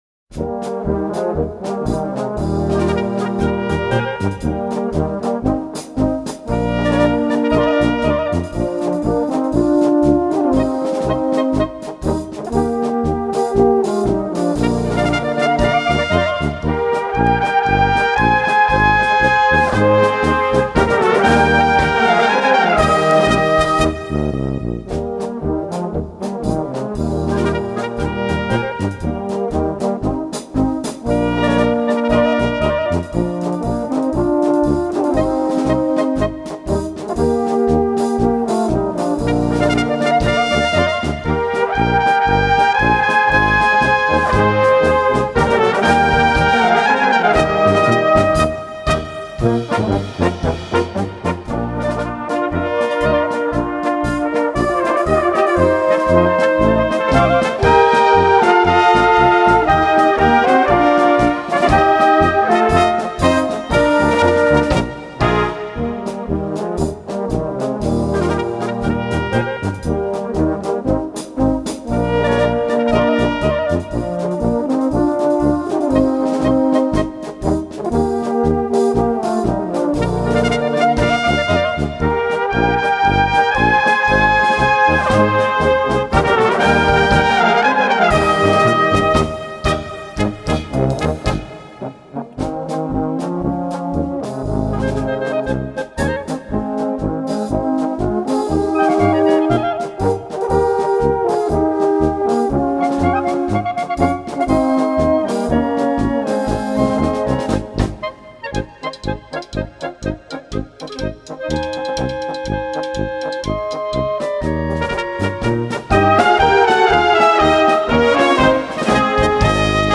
Gattung: Polka
Besetzung: Blasorchester
Eine sehr wirkungsvolle, typisch mährische Polka